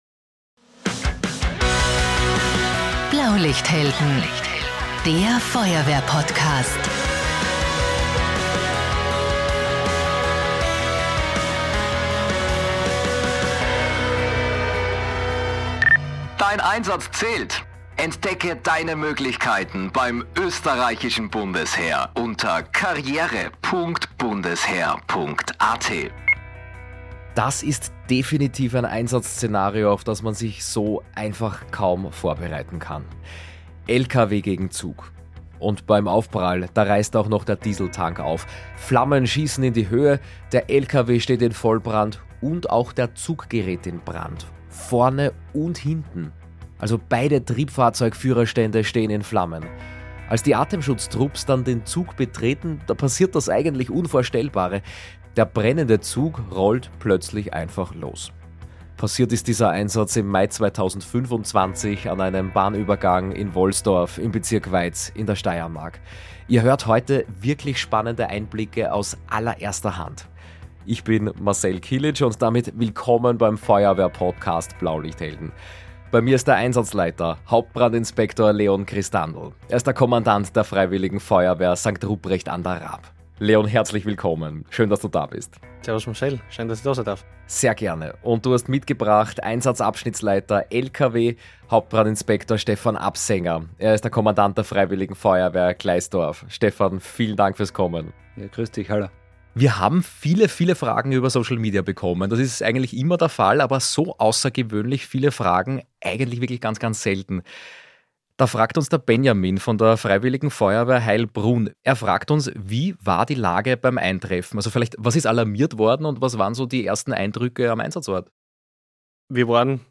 Zweimal im Monat sprechen Expertinnen und Experten über spannende Themen, die hilfreich für den Einsatzdienst sind.